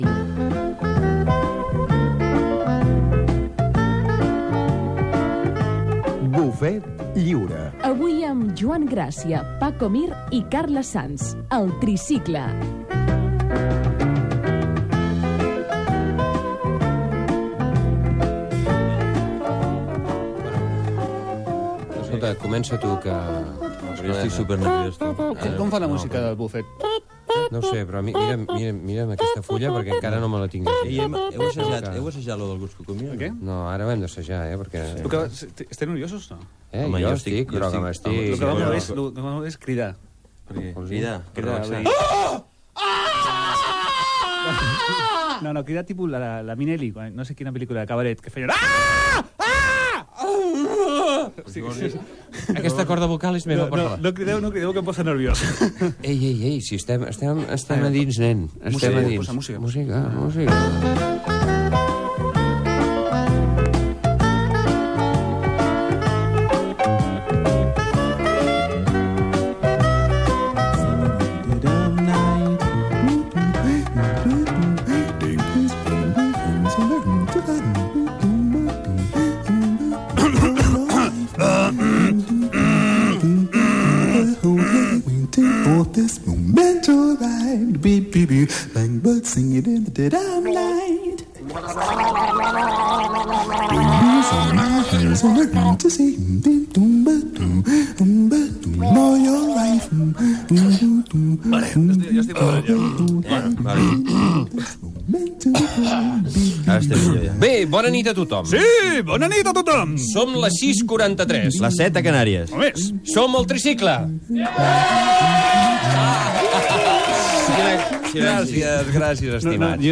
Careta del programa presentat pel Tricicle, diàleg sobre els nervis, presentació, dedicatòria, inauguració del programa, equip, telèfon del programa
Entreteniment
FM
Primera edició del programa presentat pel grup teatral Tricicle.